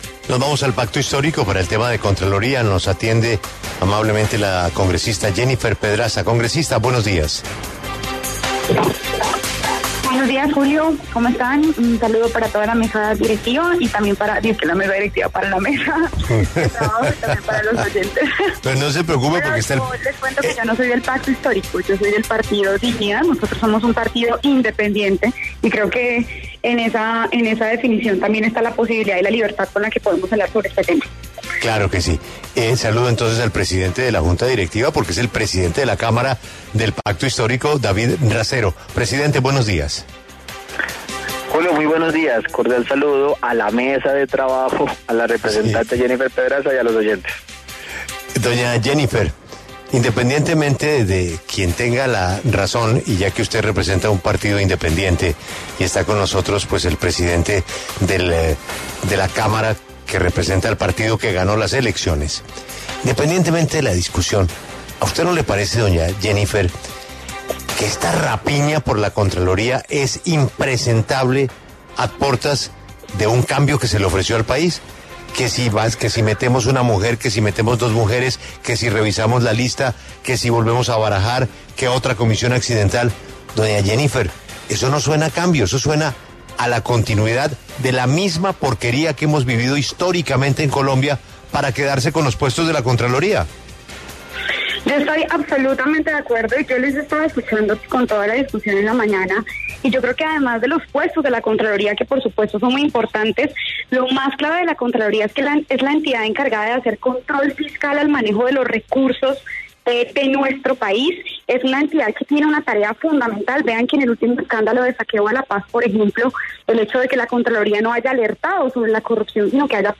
En diálogo con La W, los congresistas Jennifer Pedraza y David Racero opinan sobre el proceso de elección del próximo contralor general de la República, que reemplazará a Felipe Córdoba.